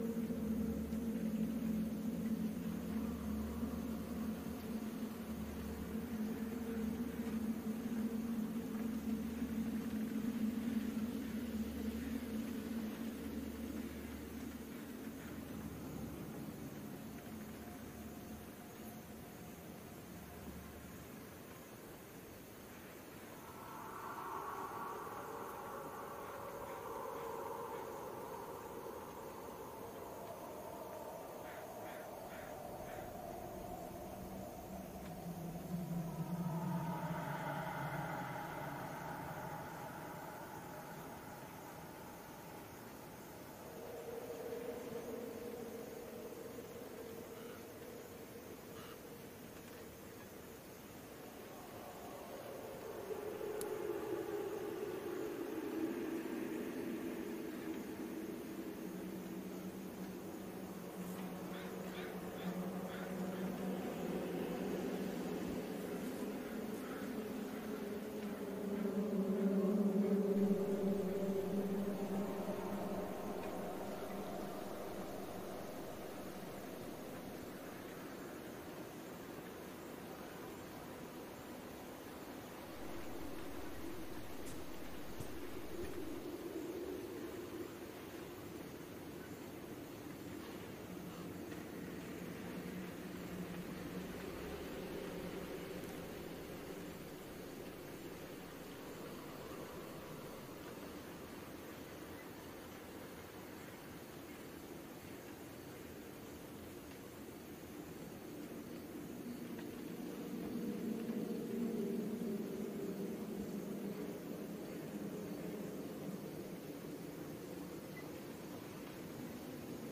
白噪声诡异室外.ogg